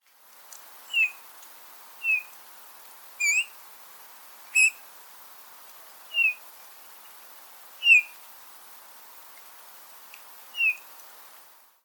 Art: Dompap (Pyrrhula pyrrhula)
Lyd: Sangen er lavmælt med gnissende toner ispedd myke fløytelåter.